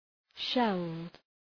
Shkrimi fonetik {ʃeld}